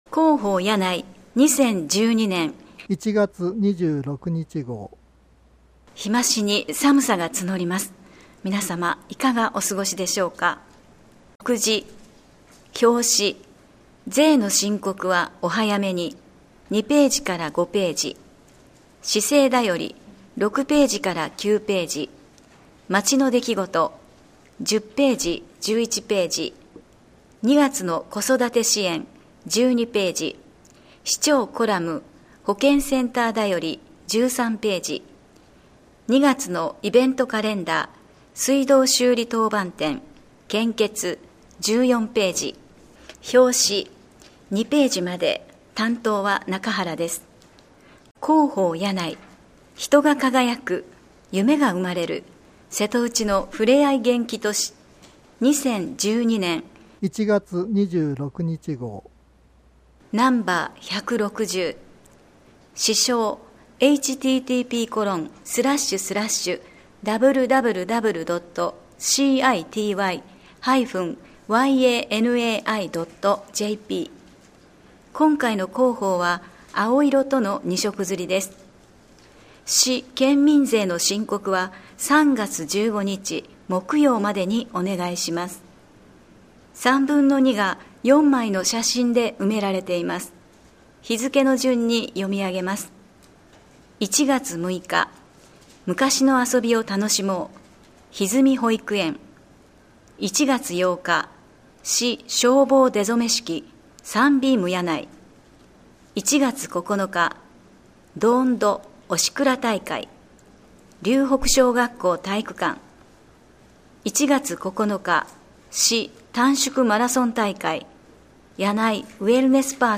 声の広報（音訳版：発行後1週間程度で利用可能）はこちらから [mp3／37.01MB]